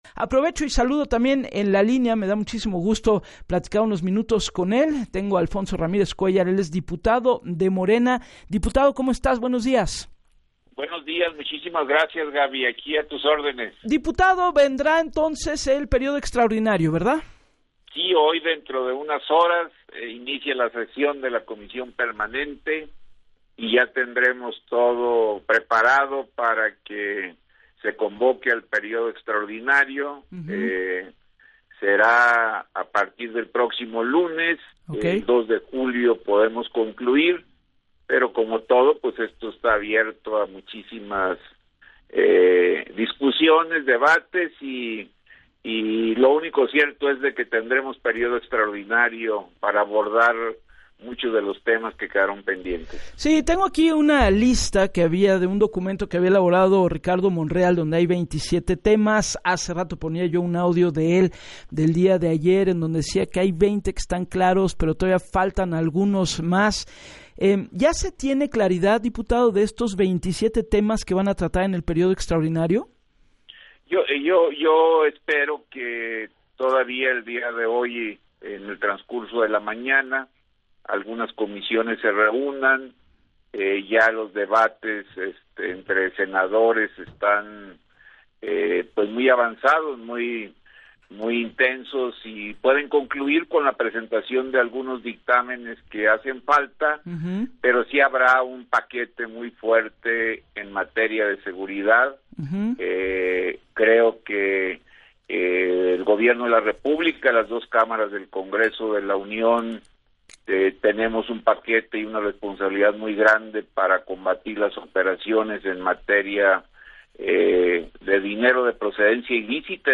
En entrevista para “Así las Cosas” con Gabriela Warkentin, detalló que en el periodo extraordinario abordará 27 temas de los cuales hay algunos pendientes “espero que el día de hoy por la mañana algunas comisiones se reúnan y puedan concluir con la presentación de algunos dictámenes que hacen falta”.